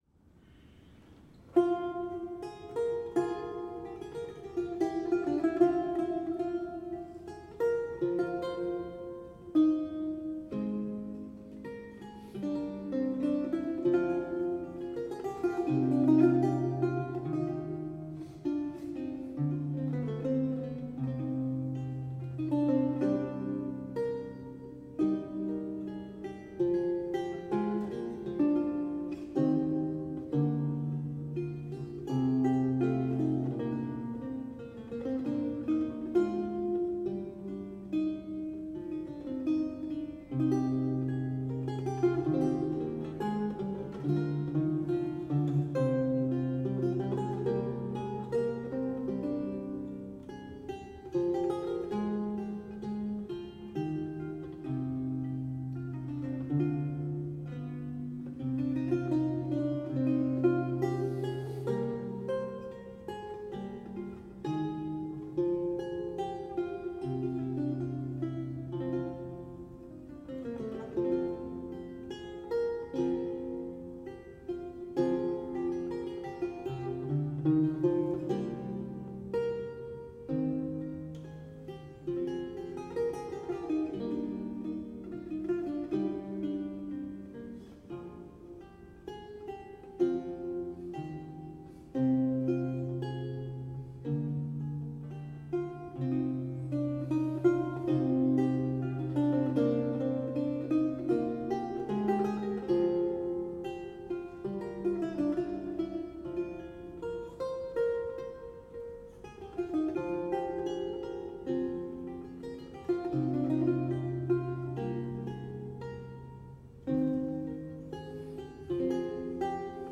a 16th century lute music piece originally notated in lute tablature
Audio recording of a lute piece from the E-LAUTE project